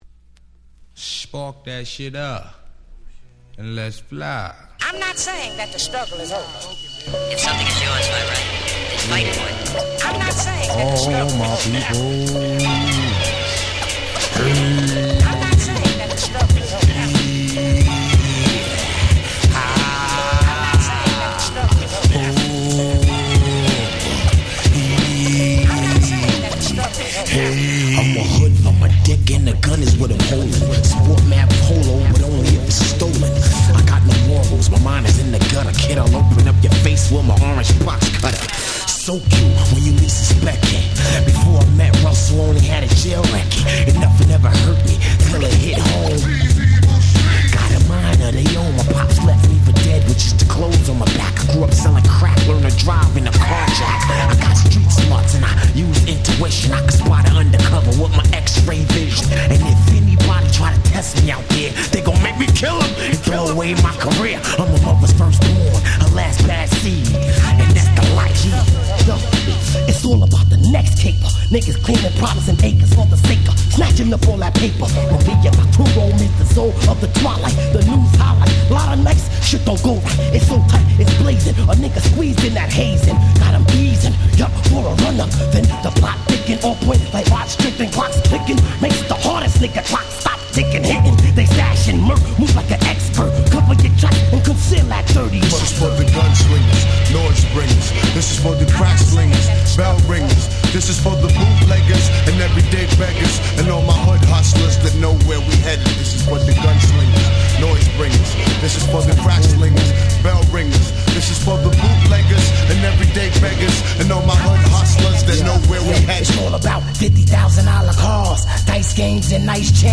95' Dope Hip Hop !!